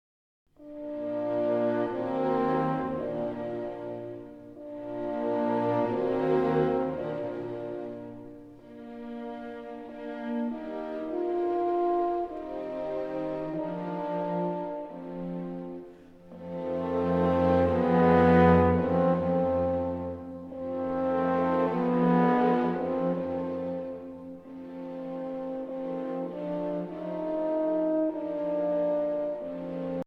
Horn and Orchestra